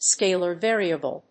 scalar+variable.mp3